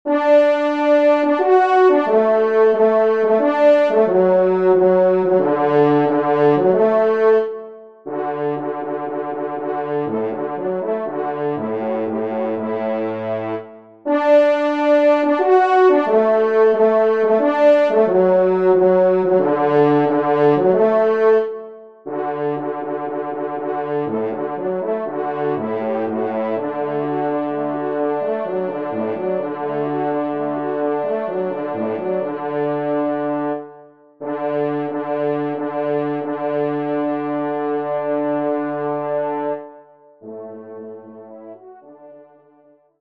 Genre : Divertissement pour Trompes ou Cors
Trompe Basse  (en exergue)